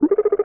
sci-fi_scan_target_01.wav